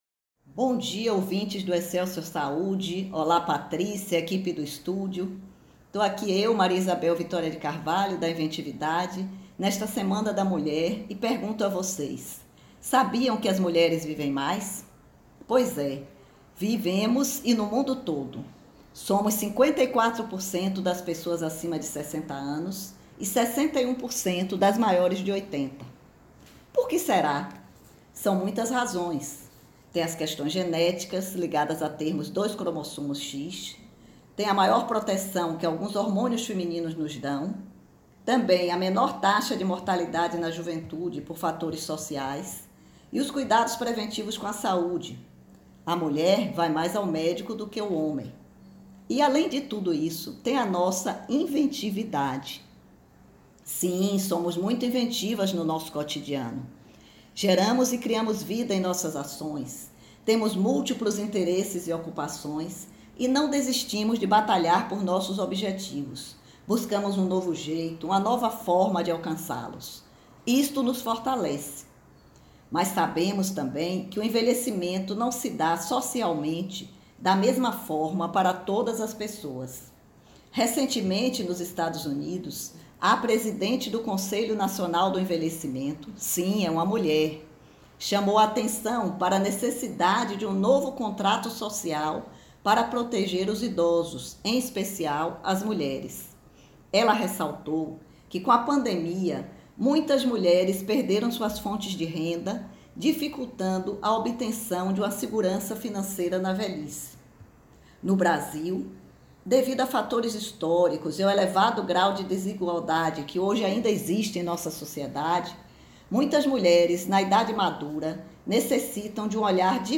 O Inventividade esteve em pauta nesta terça-feira (09.03), no Programa Excelsior Saúde, homenageando o mês da mulher, com  o tema: Por que as mulheres vivem mais?